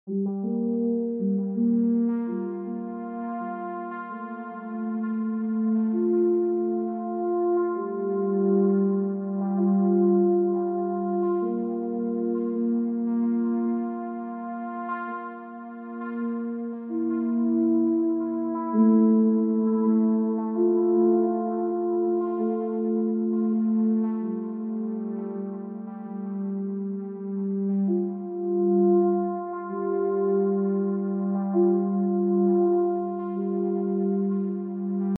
Octave 2
Une note sur 5 : Tous les 2 temps et demi
Durée : 2 temps et demi
Cette méthode est redoutable pour créer des nappes éthérées et instables qui pourraient être facilement intégrables dans des compositions électros plus conventionnelles.